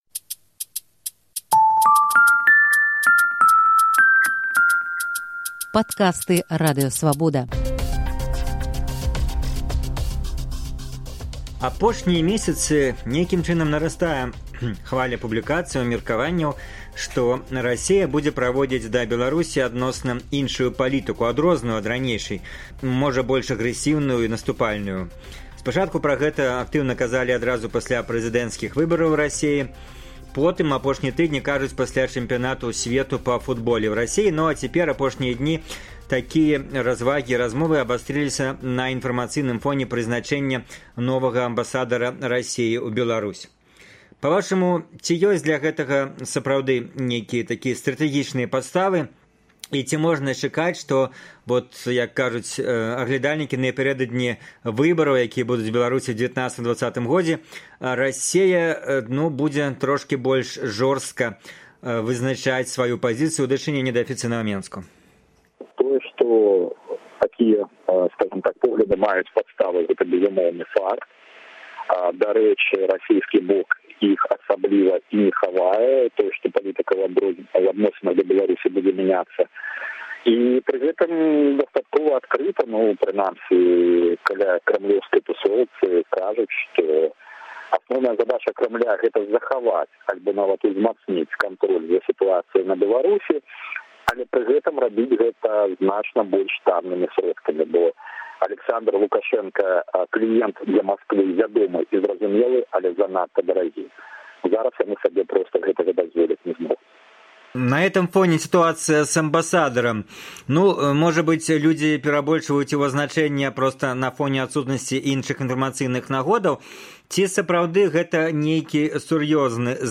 Госьць «Інтэрвію тыдня»